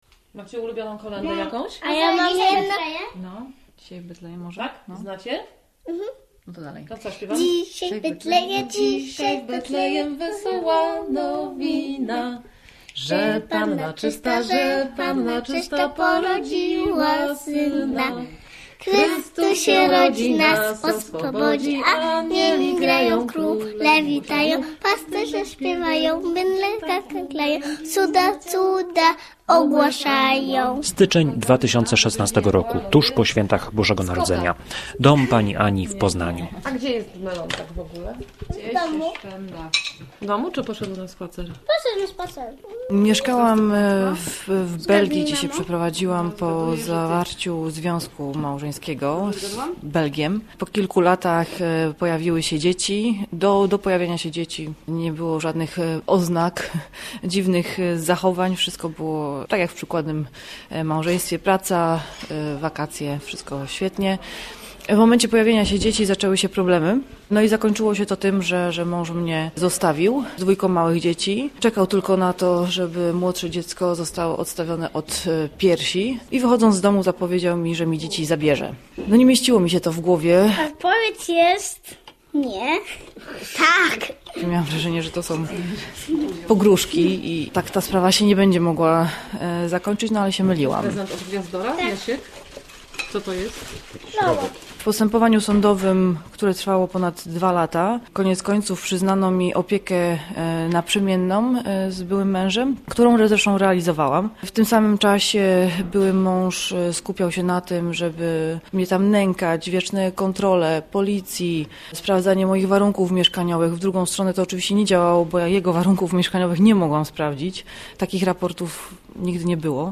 Matka Polka - reportaż